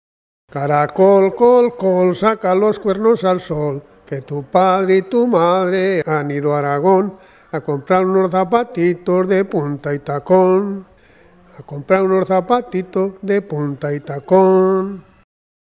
Clasificación: Conjuros infantiles
Localidad: Carbonera
Lugar y fecha de recogida: Logroño, 8 de julio de 2004